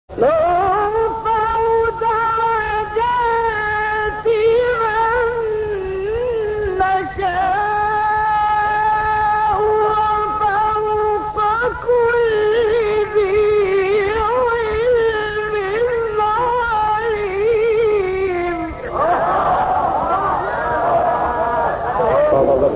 گروه شبکه اجتماعی: مقاطع صوتی از تلاوت‌های قاریان برجسته مصری را می‌شنوید.